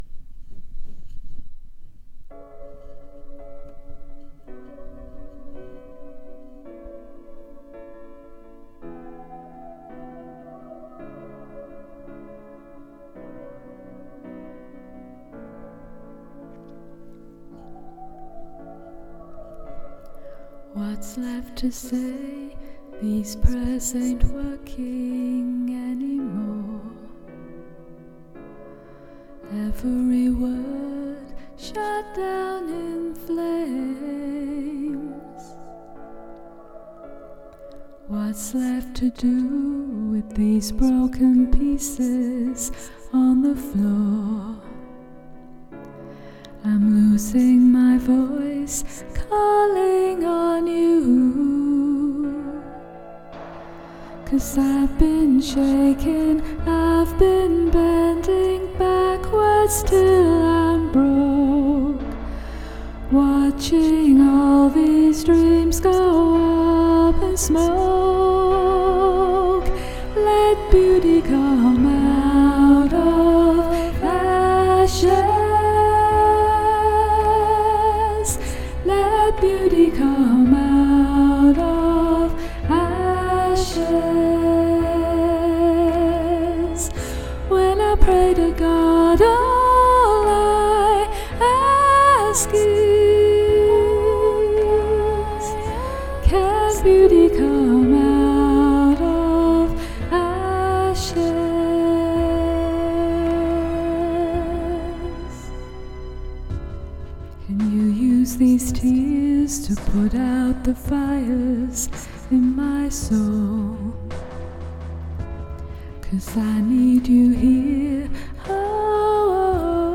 ashes-choir-practice-version1.mp3